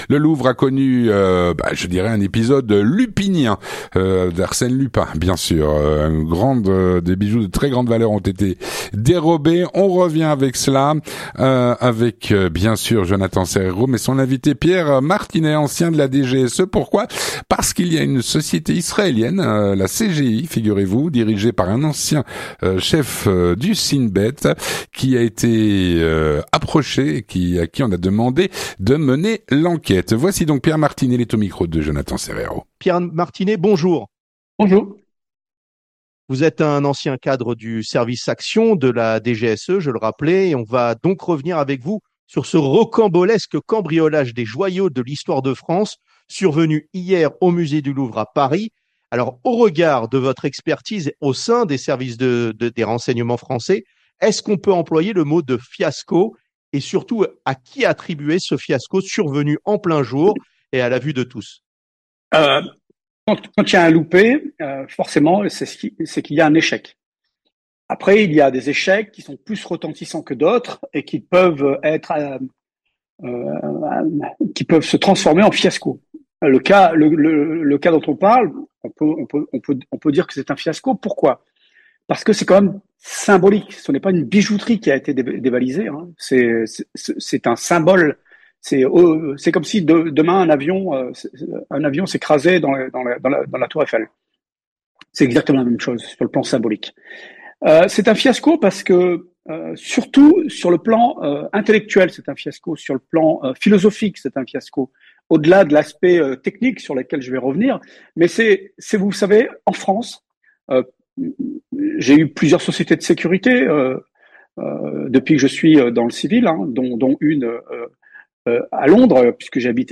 L'entretien du 18H - Le "casse" du Louvre.